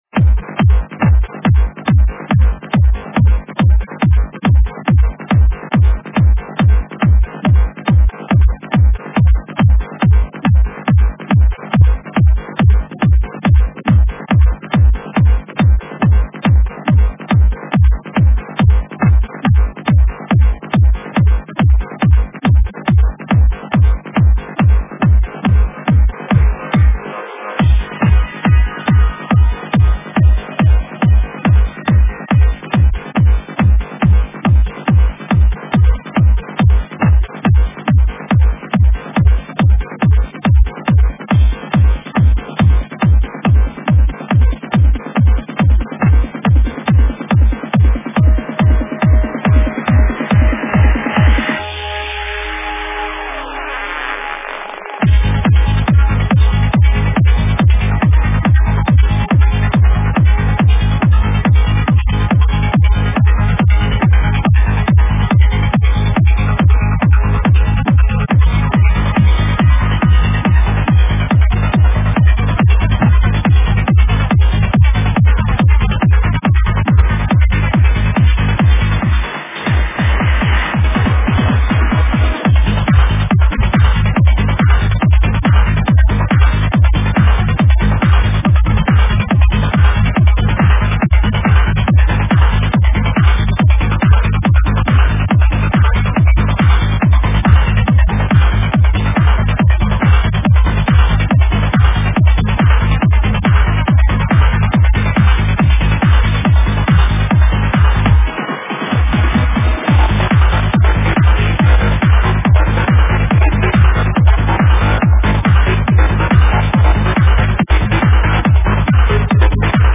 Стиль: Trance / Tech Trance